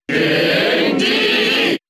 King Dedede's cheer in the US and PAL versions of Brawl.
King_Dedede_Cheer_English_SSBB.ogg